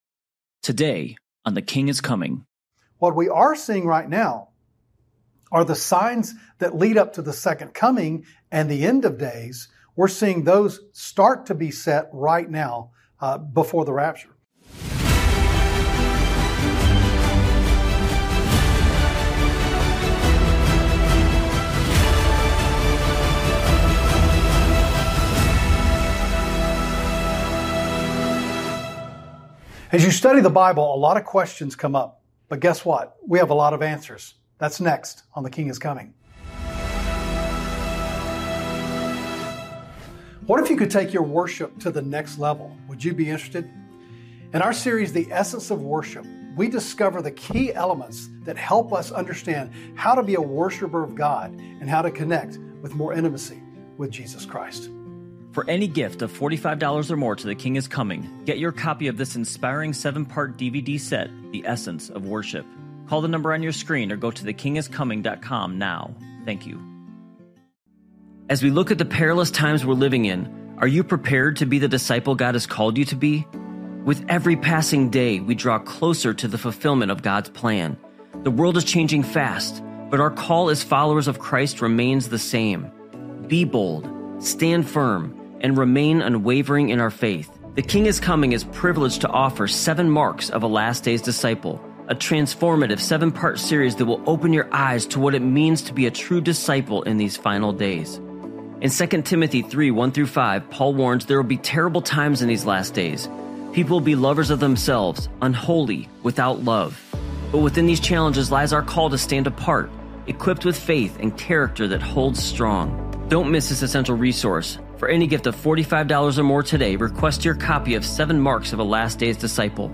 Talk Show Episode, Audio Podcast, The King Is Coming and Your Bible Questions Answered on , show guests , about Your Bible Questions Answered, categorized as Education,Health & Lifestyle,History,Love & Relationships,Philosophy,Religion,Christianity,Self Help,Society and Culture